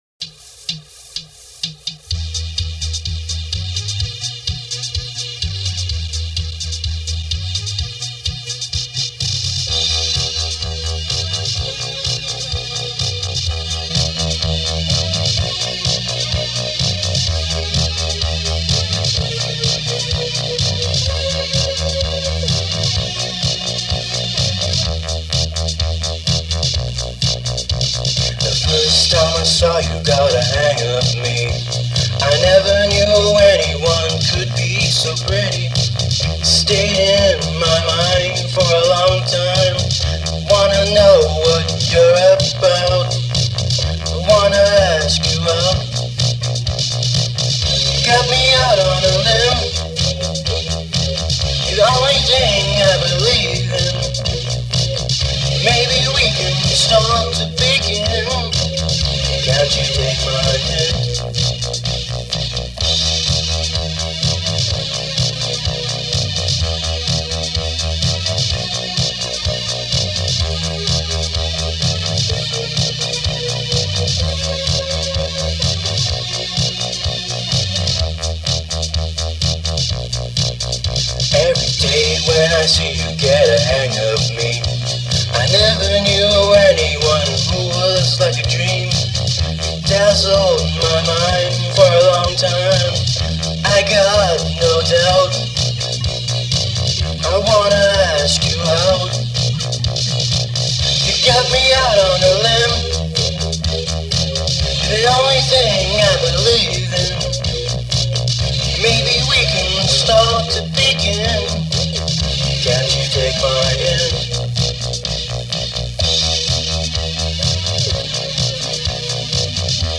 I think it's a silly little tune--hey, I was 16 years old.